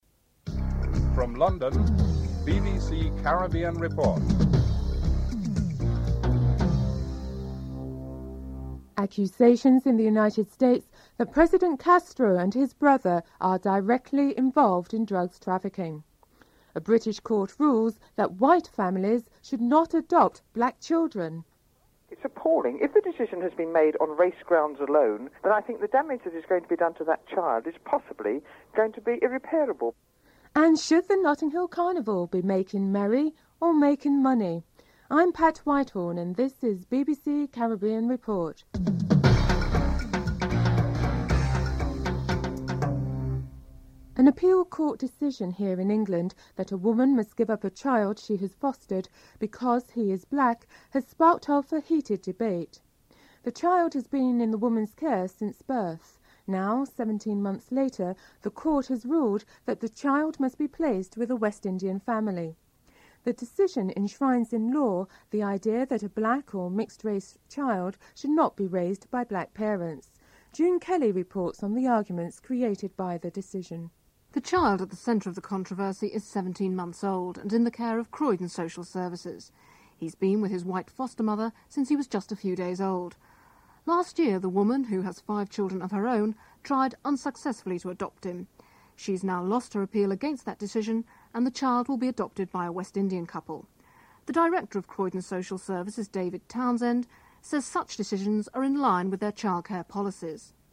The British Broadcasting Corporation
1. Headlines (00:40-01:15)